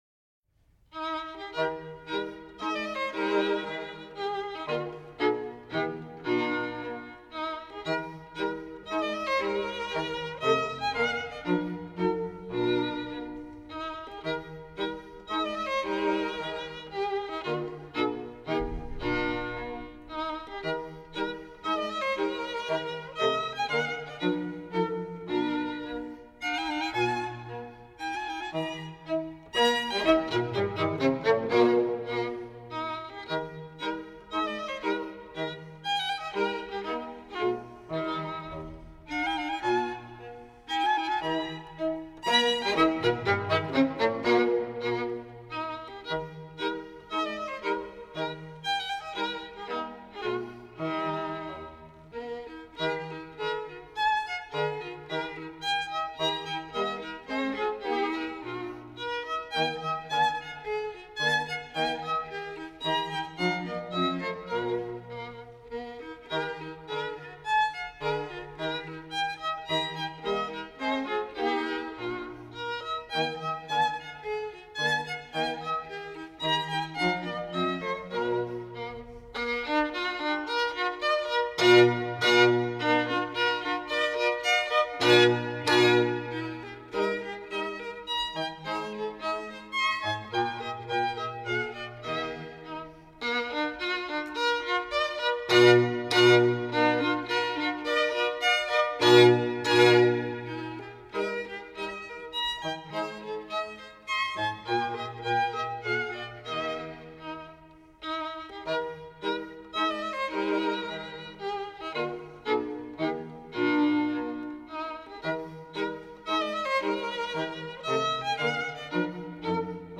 不仅唱片的录音效果很好